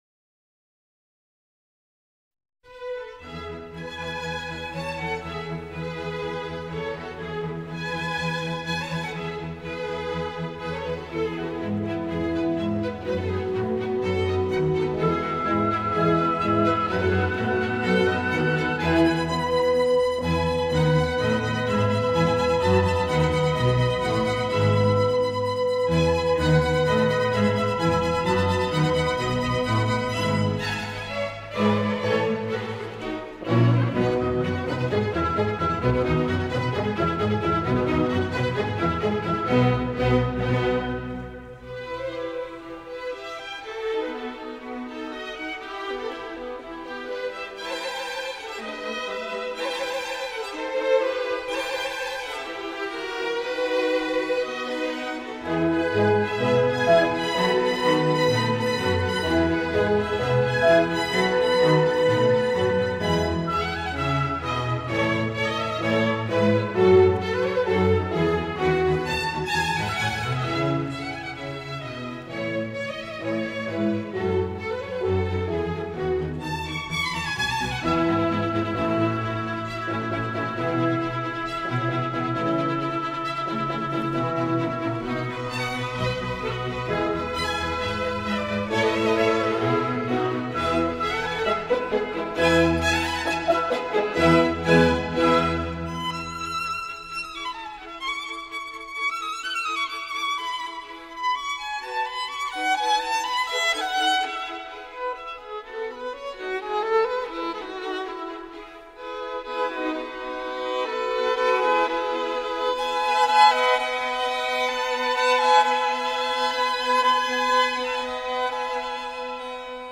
موسیقی کلاسیک: Josef Mysliveček - Violin Concerto in E major